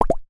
pop4.mp3